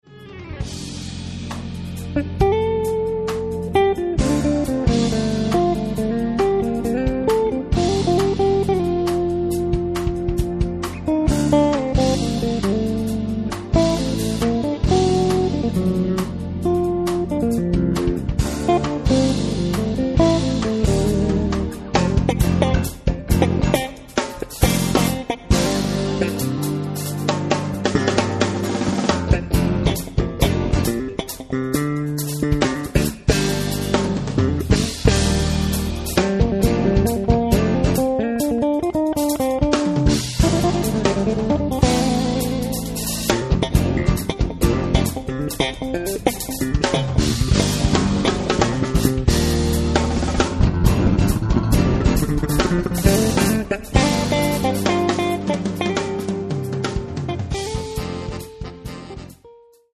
basso elettrico
That's fusion